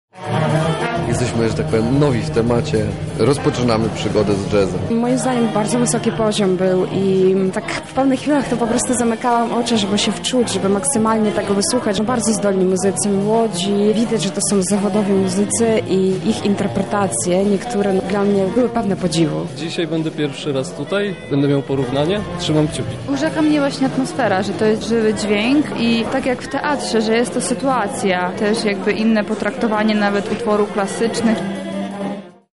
Sprawdziliśmy co słuchaczom zapadło w pamięci po pierwszych koncertach.